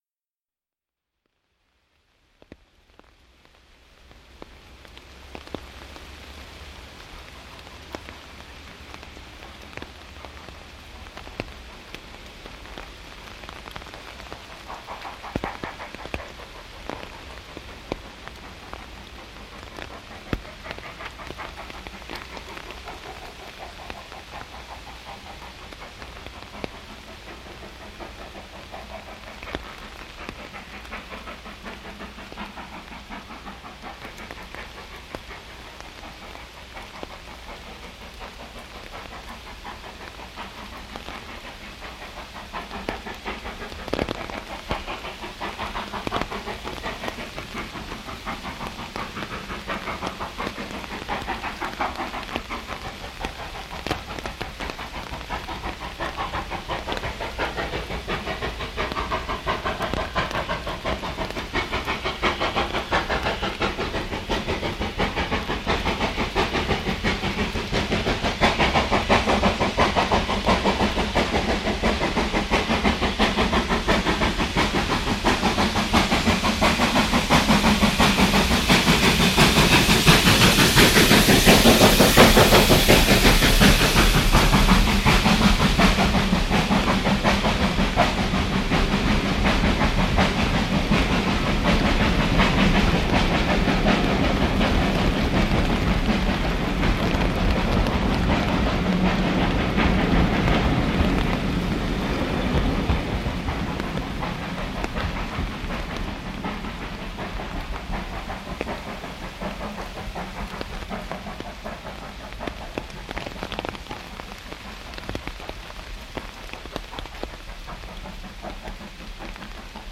80135  mit regulärem Zug nach Pickering, aufgenommen bei Beck Hole, um 14:10h am 06.10.1990. Es regnet weiter... .